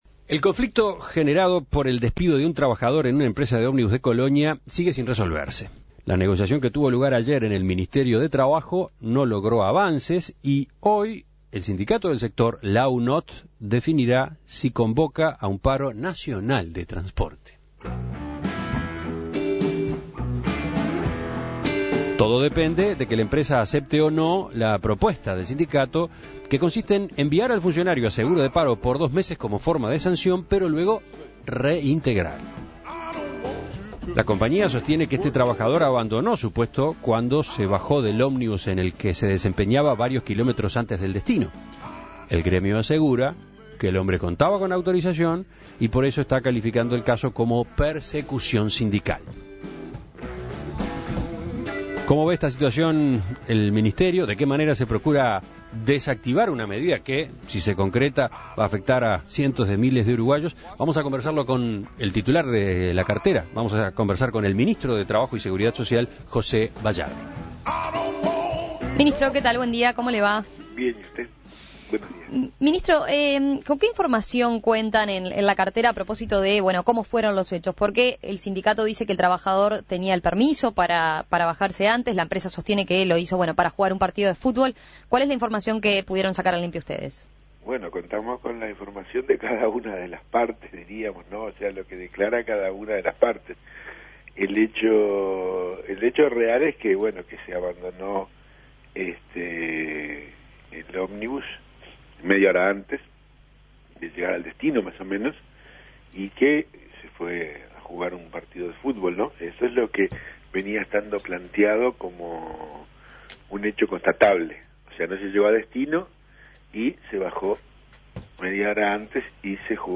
La posibilidad de un paro nacional por ahora se desactivó. Para tener la visión del Ministerio de Trabajo, En Perspectiva dialogó con el ministro José Bayardi.